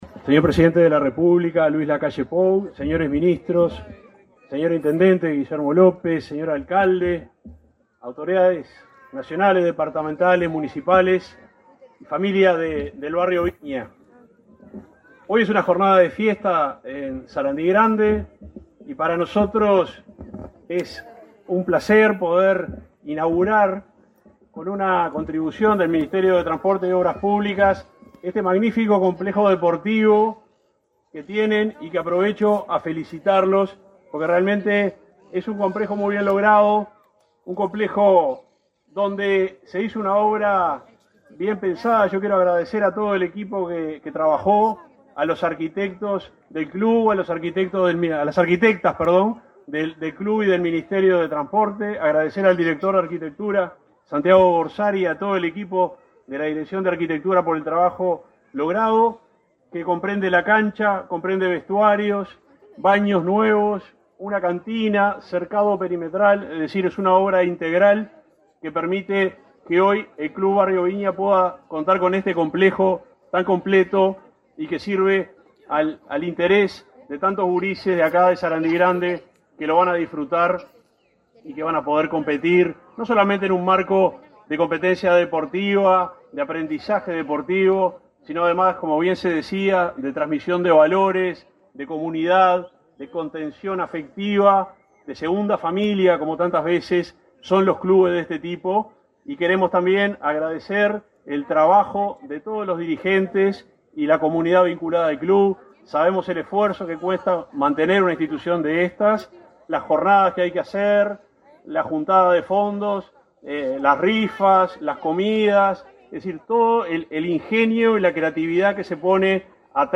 Palabras del subsecretario de Transporte y Obras Públicas, Juan José Olaizola
El presidente de la República, Luis Lacalle Pou, asistió, este 12 de octubre, a la inauguración de un complejo en el Club Deportivo y Social Barrio Viña, en Sarandí Grande, Florida. Las obras se efectuaron con un aporte de más de 4 millones de pesos, proveniente del Ministerio de Transporte y Obras Públicas (MTOP).